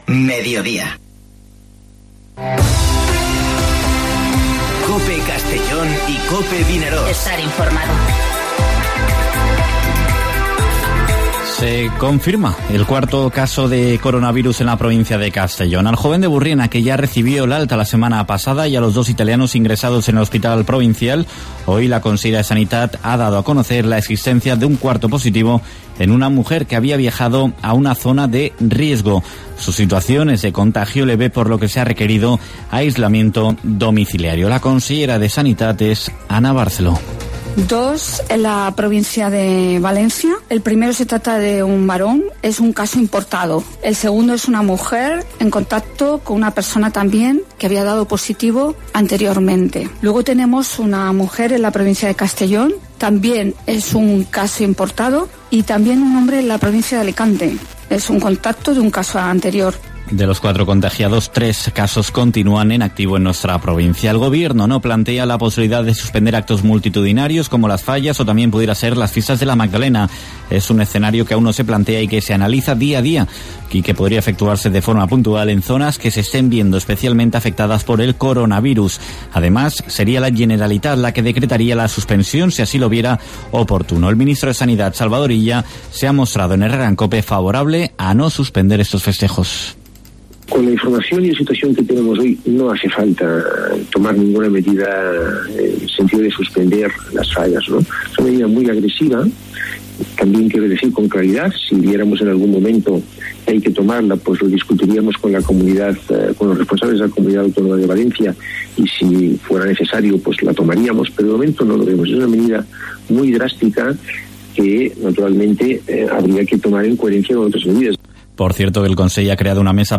Informativo Mediodía COPE en la provincia de Castellón (09/03/2020)